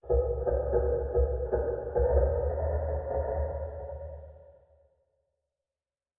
Minecraft Version Minecraft Version 25w18a Latest Release | Latest Snapshot 25w18a / assets / minecraft / sounds / ambient / nether / basalt_deltas / heavy_click1.ogg Compare With Compare With Latest Release | Latest Snapshot
heavy_click1.ogg